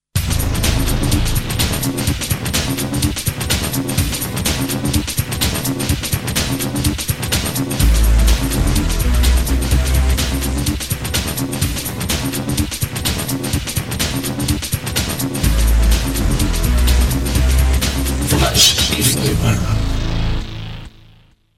Música i identificació